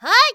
qyh普通5.wav 0:00.00 0:00.35 qyh普通5.wav WAV · 30 KB · 單聲道 (1ch) 下载文件 本站所有音效均采用 CC0 授权 ，可免费用于商业与个人项目，无需署名。
人声采集素材